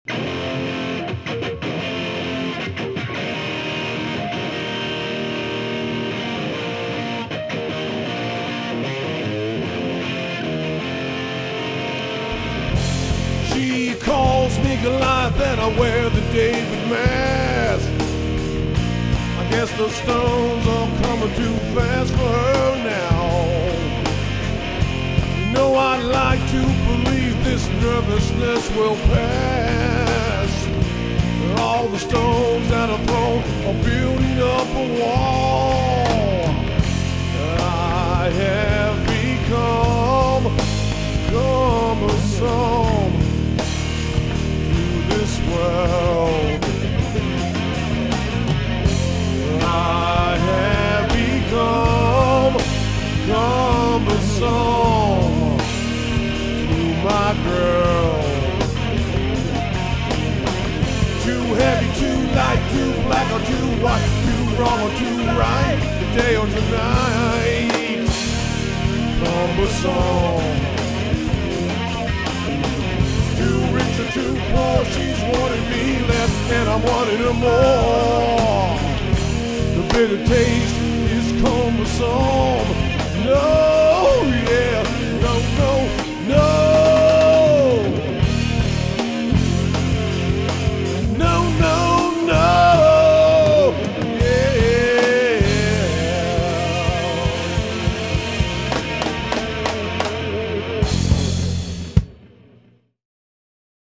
drums & background vocals
bass
guitar & background vocals
lead vocals